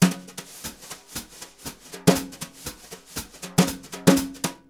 Index of /90_sSampleCDs/Univers Sons - Jazzistic CD 1 & 2/VOL-1/03-180 BRUSH